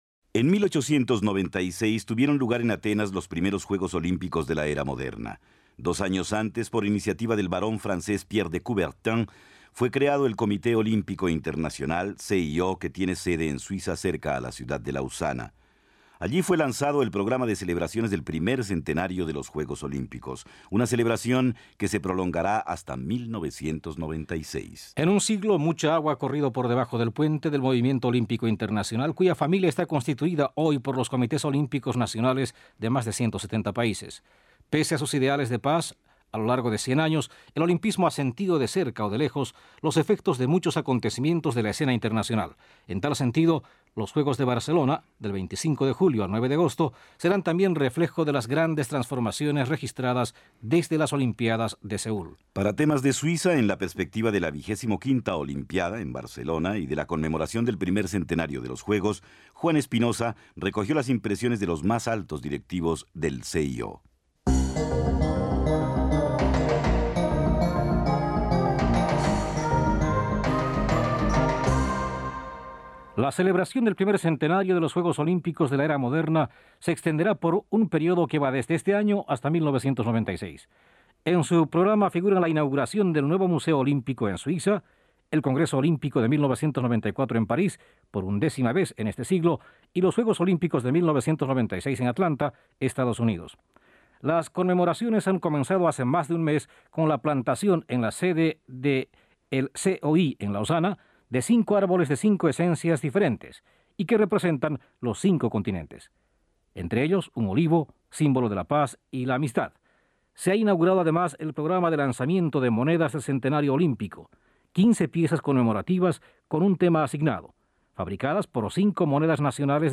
Un siglo después, el Comité Olímpico Internacional (COI), con sede en Lausana, conmemoraba este aniversario. Declaraciones del entonces presidente del COI, Juan Antonio Samaranch. (Archivos Radio Suiza Internacional)